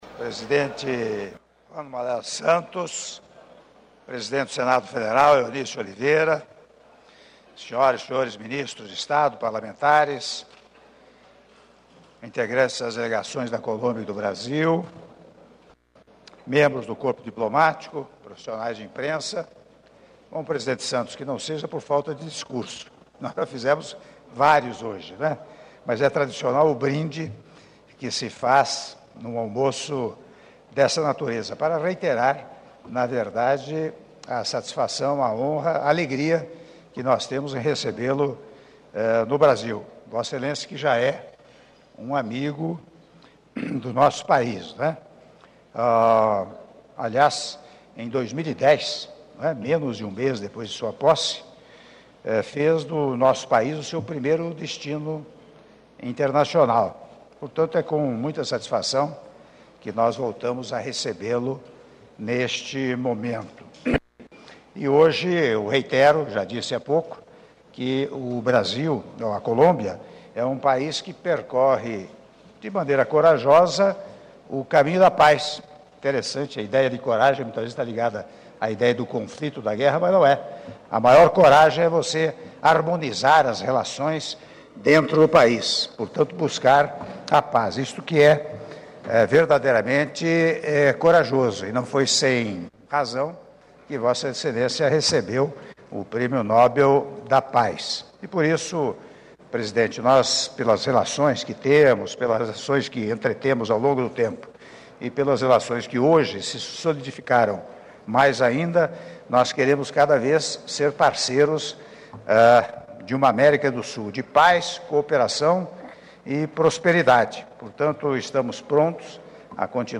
Áudio do brinde do Presidente da República, Michel Temer, durante o Almoço em homenagem ao Senhor Juan Manuel Santos, Presidente da República da Colômbia - (03min09s) - Brasília/DF — Biblioteca